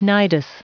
Prononciation du mot nidus en anglais (fichier audio)